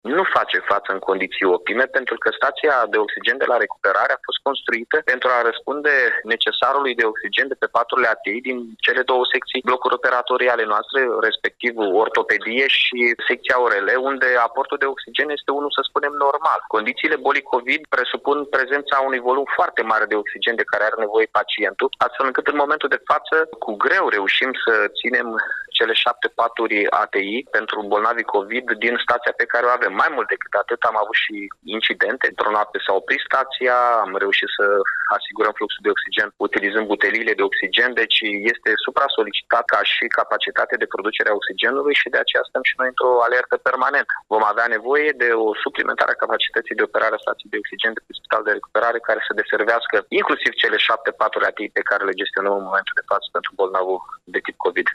Pe de altă parte, Mihai Chirica a adăugat că cele șapte paturi ATI sunt cu greu susținute, deoarece capacitatea frabricii de oxigen a spitalului este depășită şi, de aceea, va fi mărită capacitatea stației de oxigen: